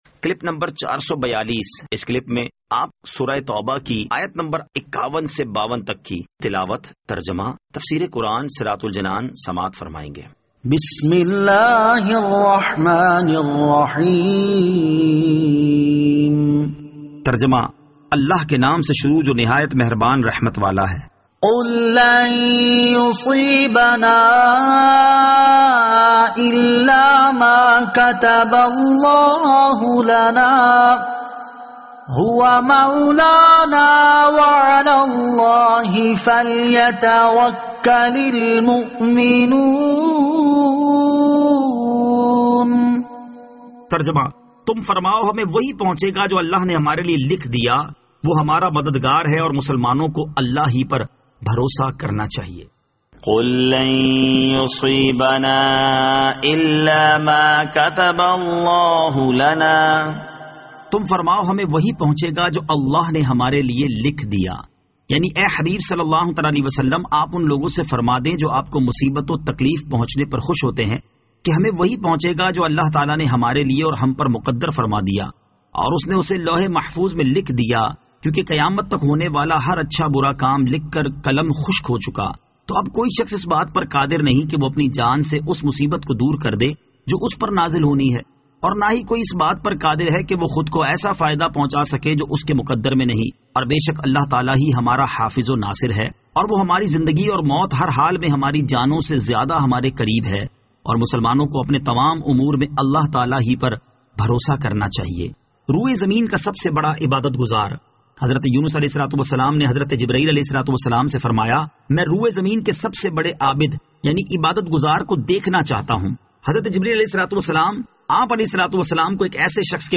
Surah At-Tawbah Ayat 51 To 52 Tilawat , Tarjama , Tafseer